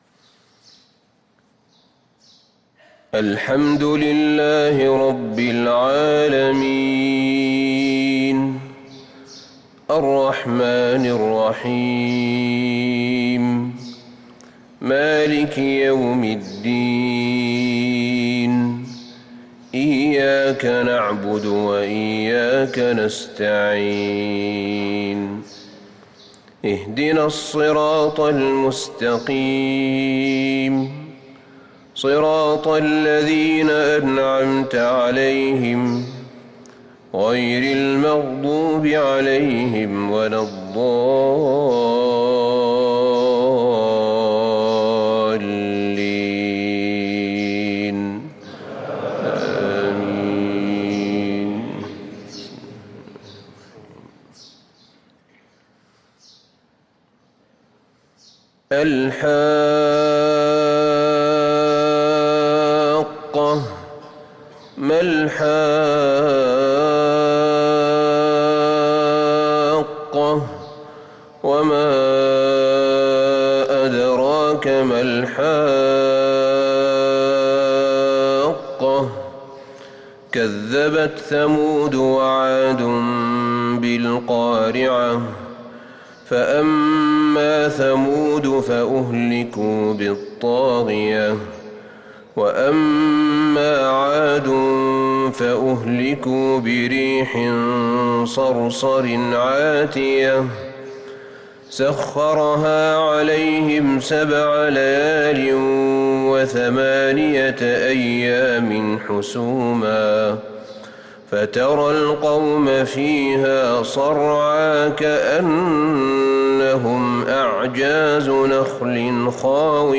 صلاة الفجر للقارئ أحمد بن طالب حميد 9 ربيع الأول 1444 هـ
تِلَاوَات الْحَرَمَيْن .